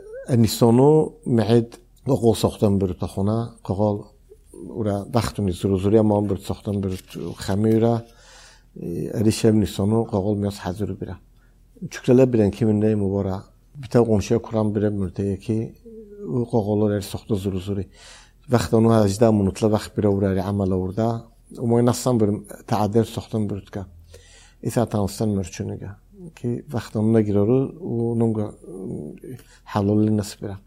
Plus I’m hearing uvular [q] and [χ~x], like many of the Central Asian languages like Uzbek, Kyrgyz, and Kazakh.
Strangely, I’m also maybe hearing something pharyngeal-like?